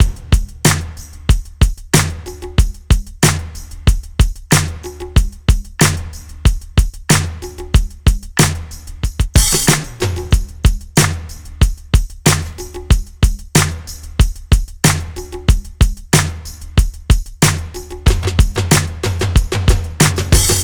09 drums.wav